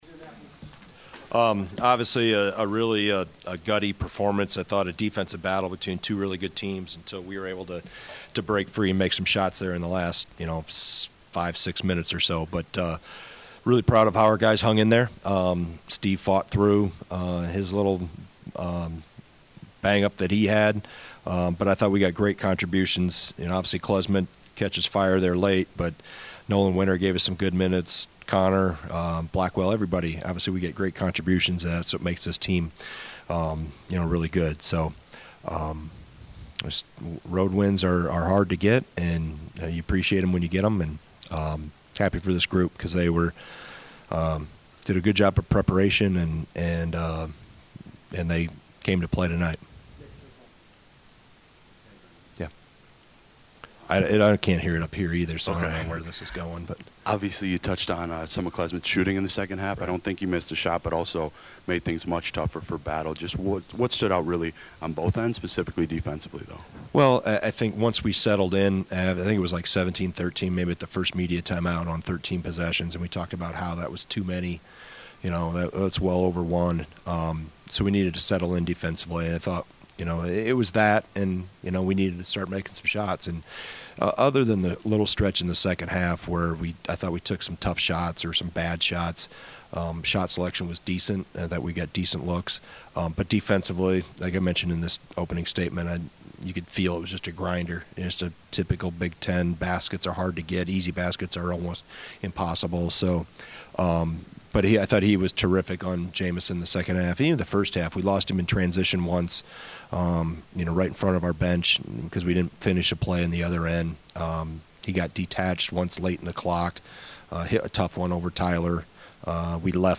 Postgame Press Conference